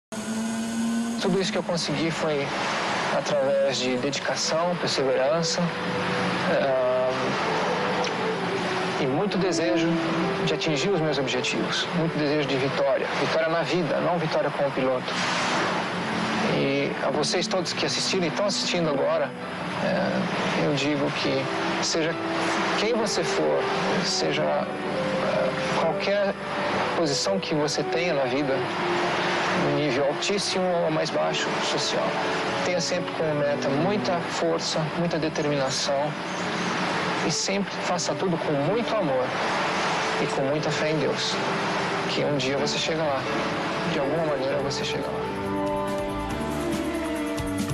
Mensagem motivacional na voz de Ayrton Senna
Mensagem Motivacional Ayrton Senna - HD 720p.mp3